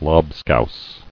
[lob·scouse]